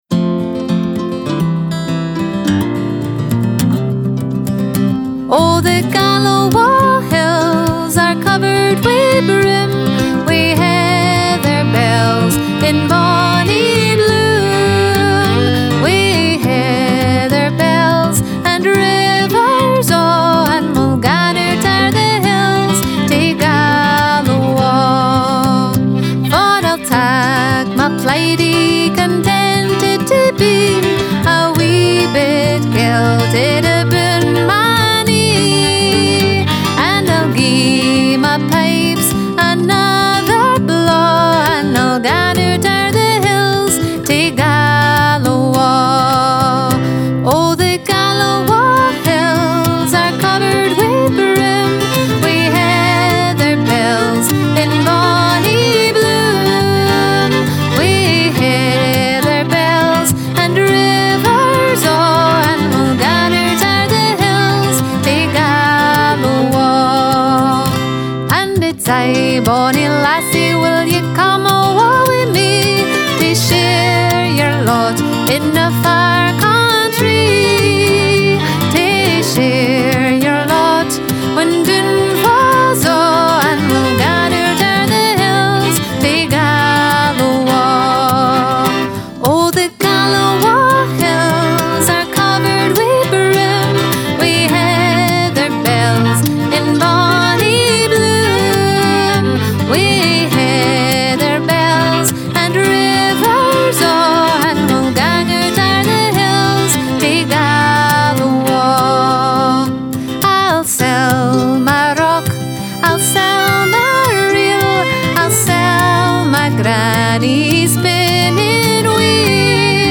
Gallowa' Hills Player Trad play stop mute max volume repeat Gallowa' Hills Scottish Music Download Gallowa' Hills MP3